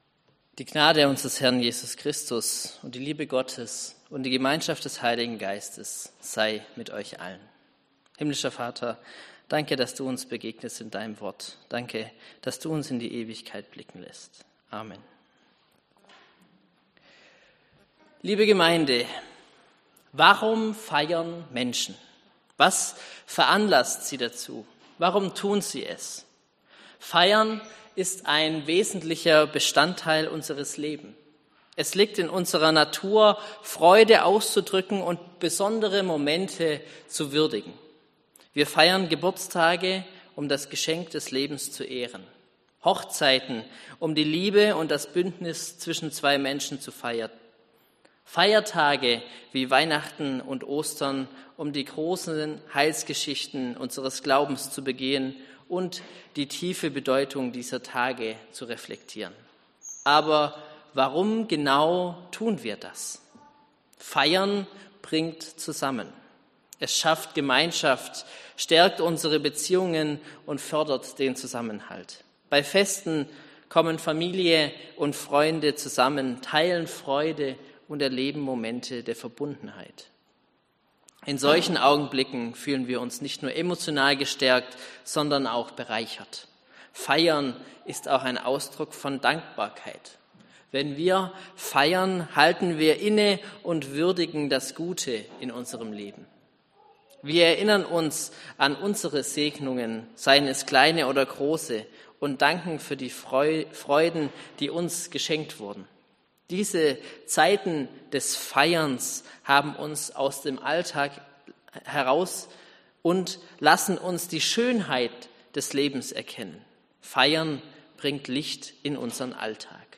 Predigt in der Sommerpredigtreihe „Feste feiern“